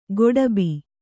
♪ goḍabi